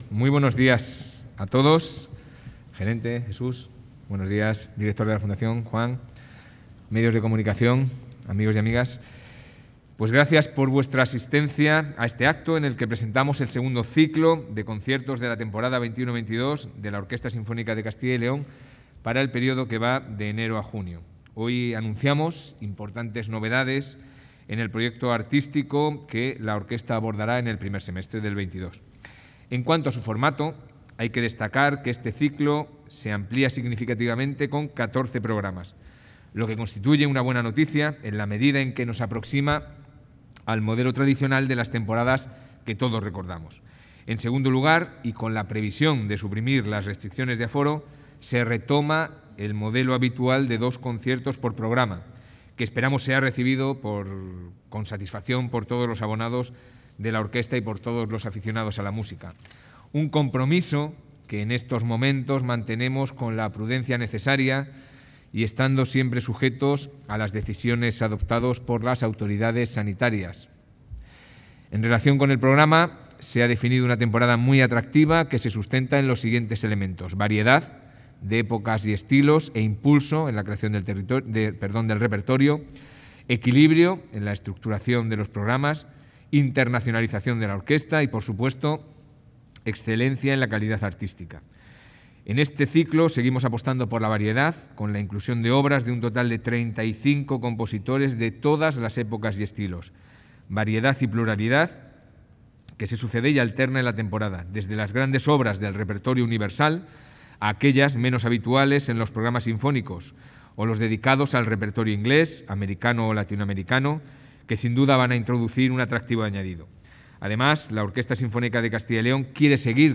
Audios de la presentación